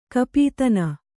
♪ kapītana